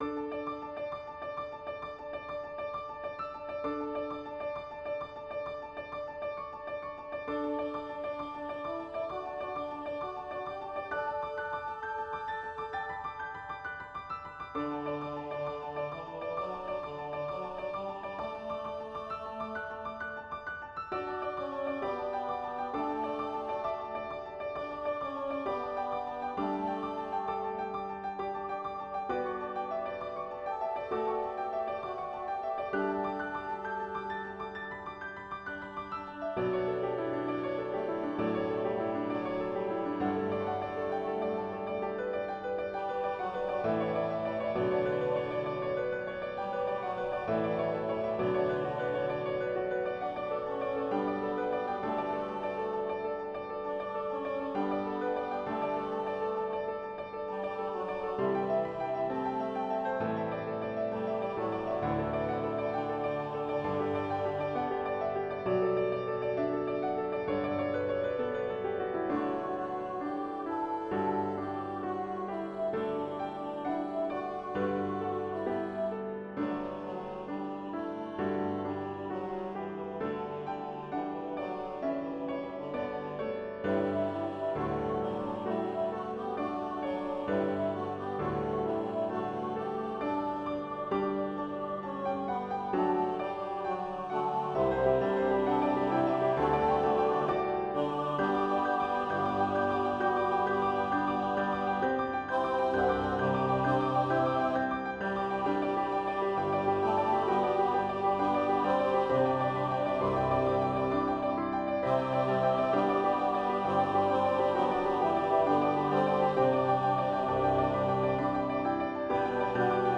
SATB Choir and Piano
Hymn arrangement